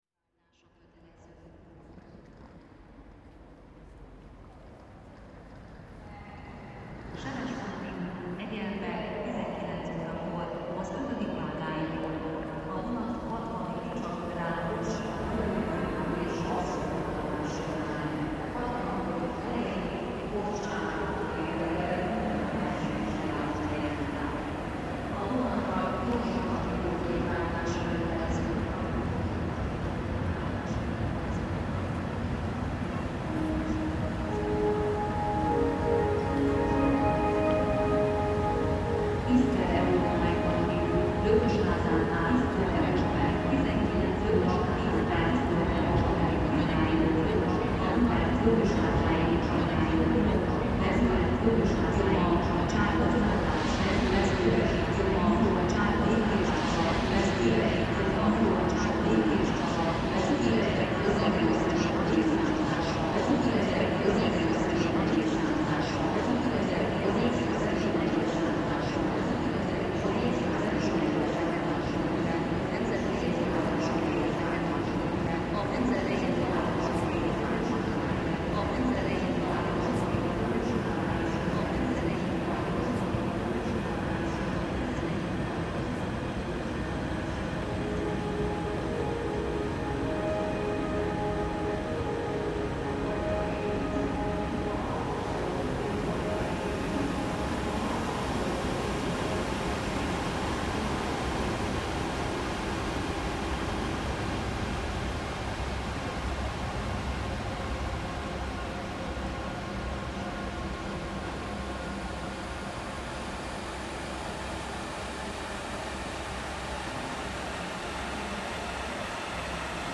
Keleti train station reimagined